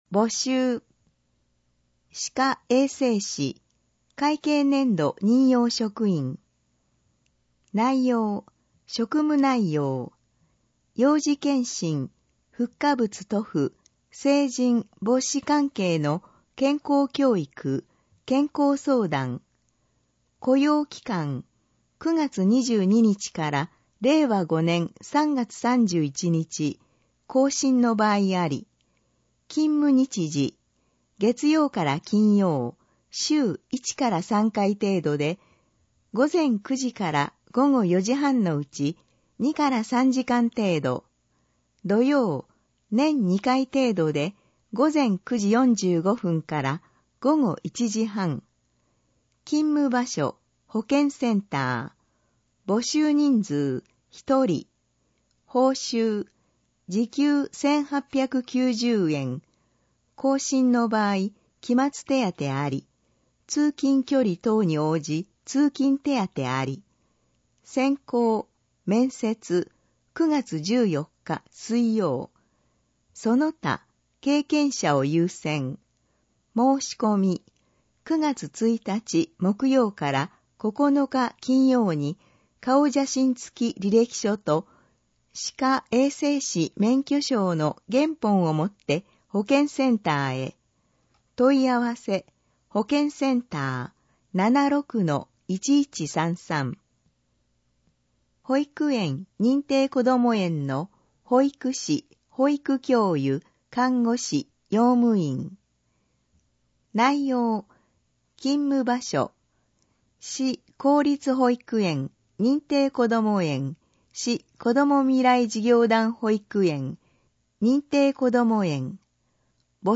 以上の音声データは、「音訳ボランティア安城ひびきの会」の協力で作成しています